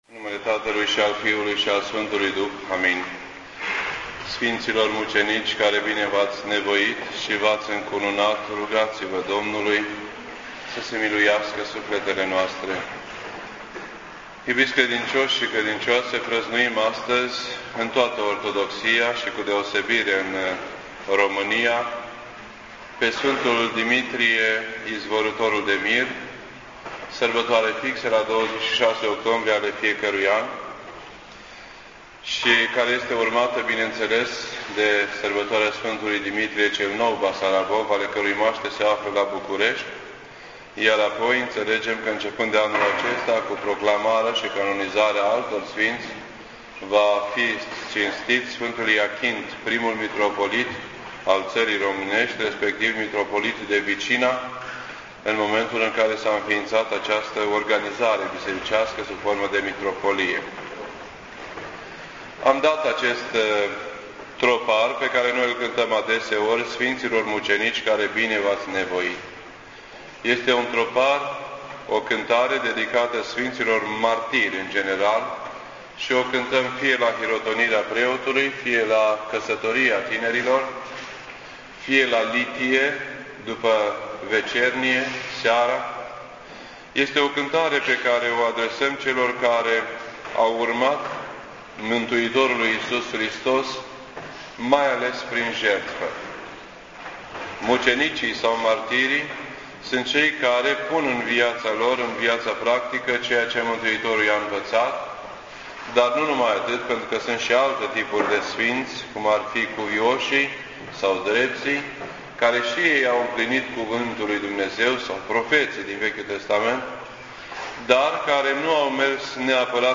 Predici ortodoxe in format audio